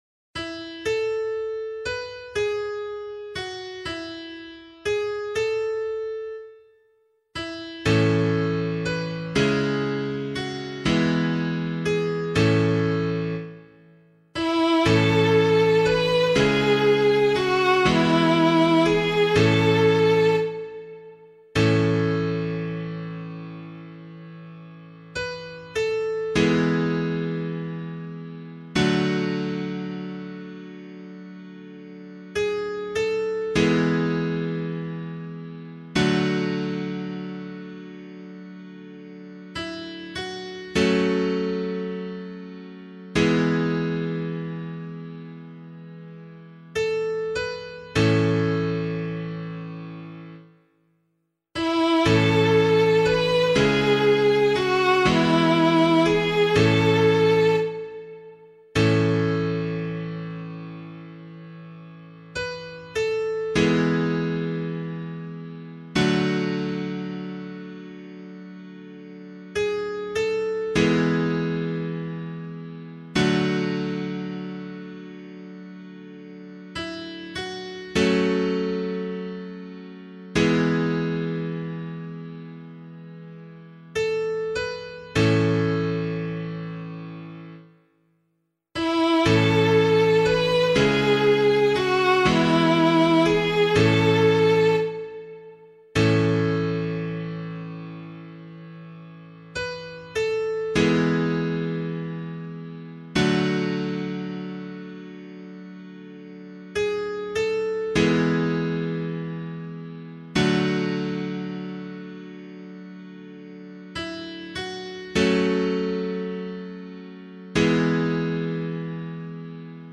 001 Advent 1 Psalm C [LiturgyShare 8 - Oz] - piano.mp3